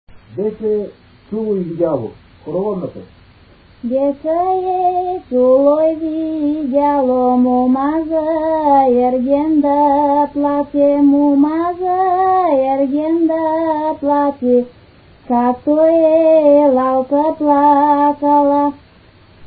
музикална класификация Песен
размер Две четвърти
фактура Едногласна
начин на изпълнение Солово изпълнение на песен
битова функция На хоро
фолклорна област Югоизточна България (Източна Тракия с Подбалкана и Средна гора)
място на записа Горно Изворово
начин на записване Магнетофонна лента